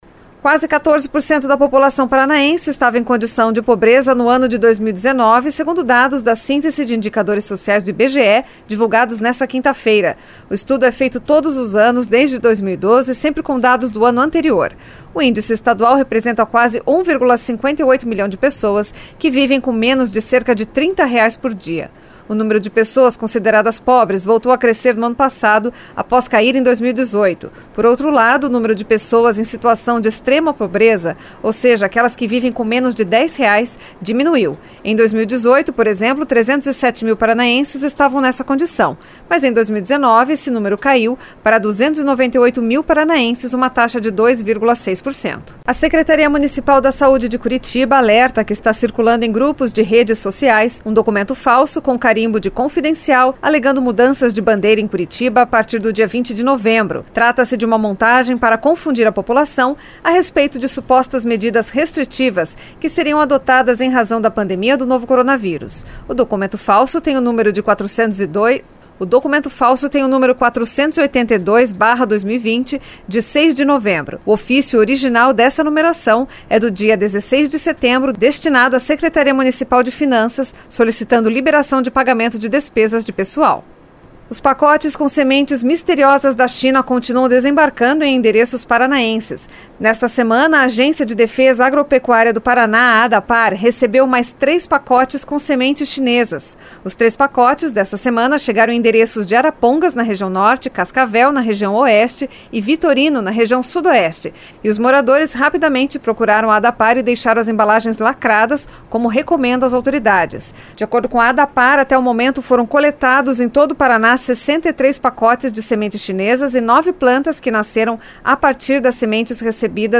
Giro de Notícias Tarde SEM TRILHA